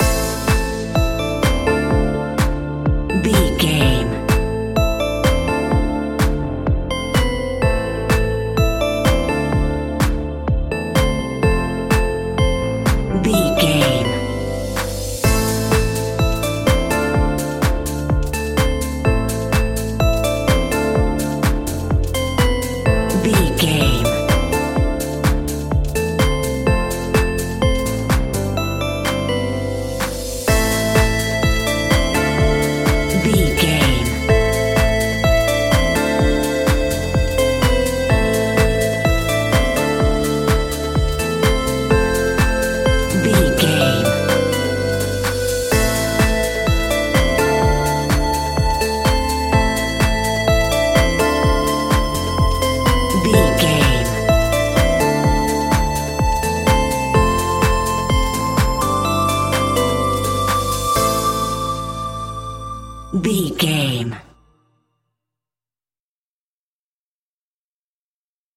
Aeolian/Minor
groovy
uplifting
drum machine
synthesiser
bass guitar
funky house
electronic
upbeat